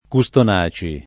[ ku S ton #© i ]